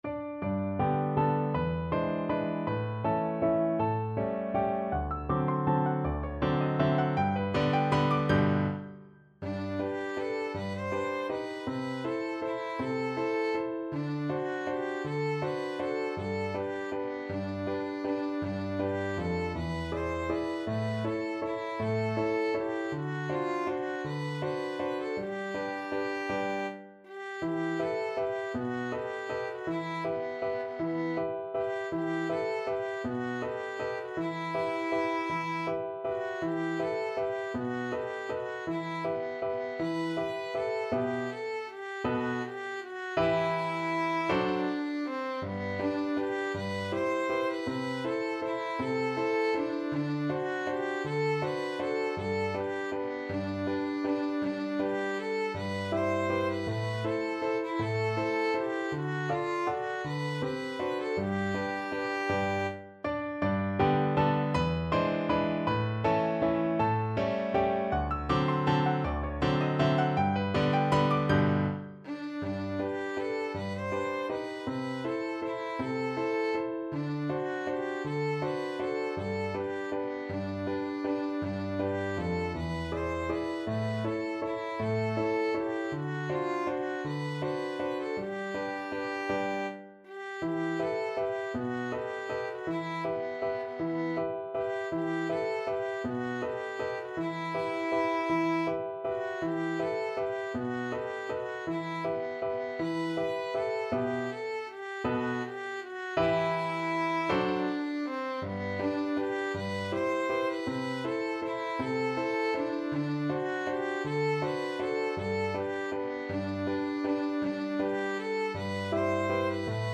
Violin
3/4 (View more 3/4 Music)
G major (Sounding Pitch) (View more G major Music for Violin )
~ = 160 Tempo di Valse
Traditional (View more Traditional Violin Music)